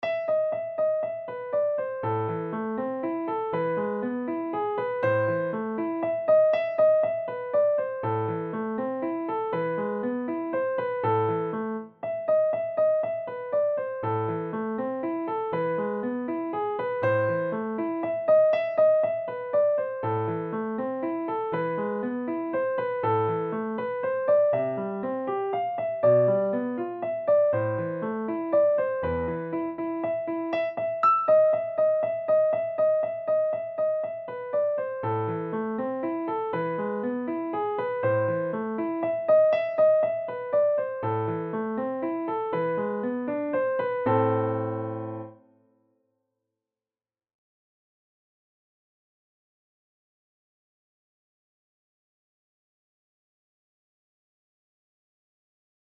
Easy/Level 3 Piano Solo